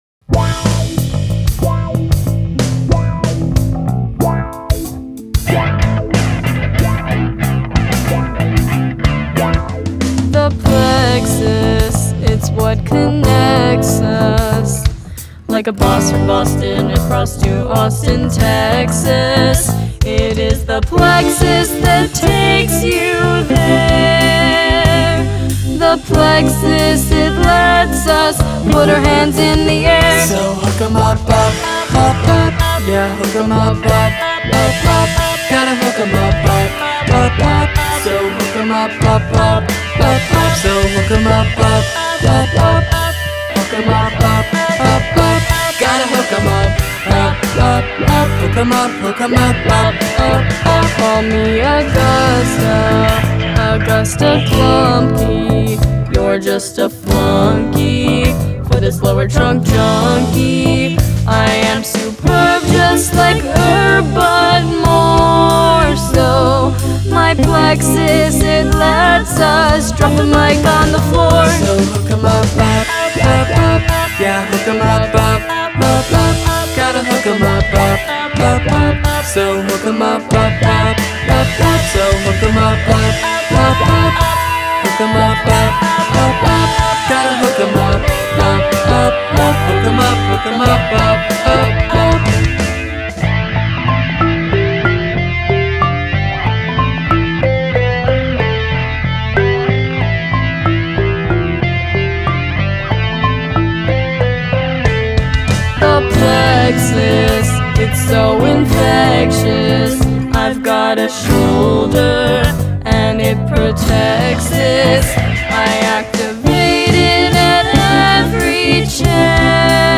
additional vocals
acoustic guitar
marimba
lead vocals